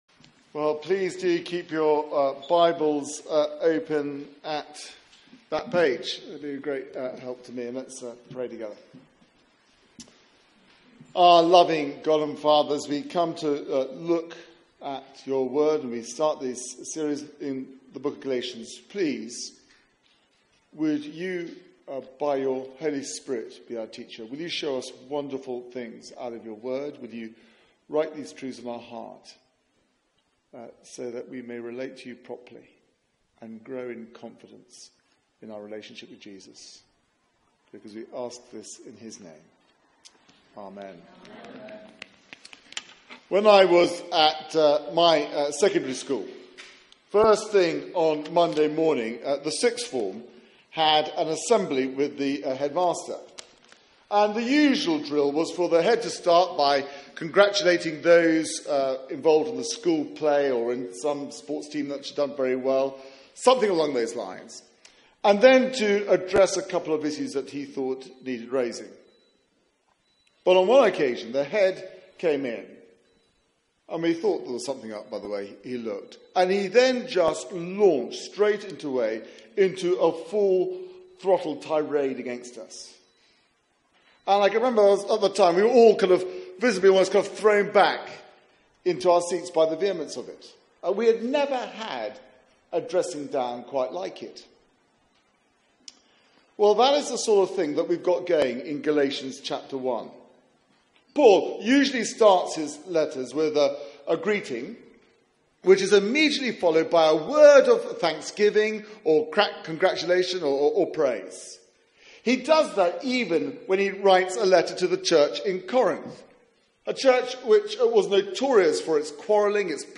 Media for 6:30pm Service on Sun 03rd Sep 2017
Theme: The one and only gospel Sermon